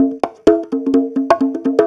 Conga Loop 128 BPM (16).wav